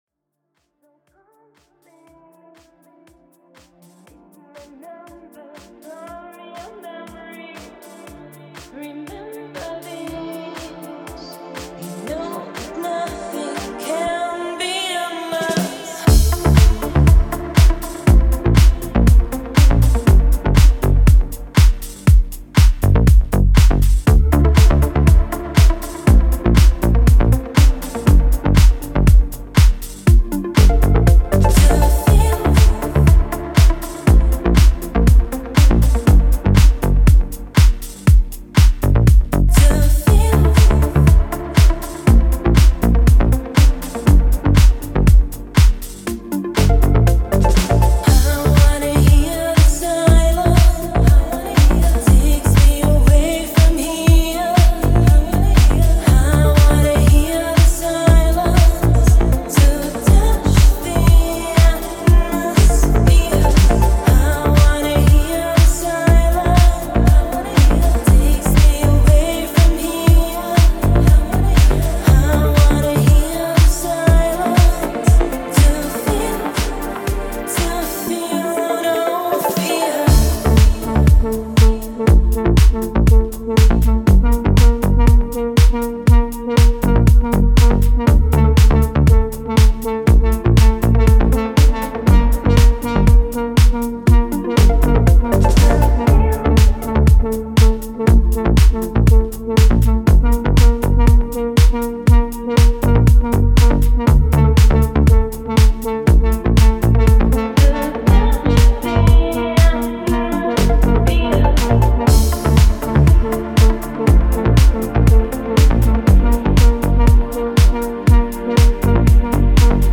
Deep House музыка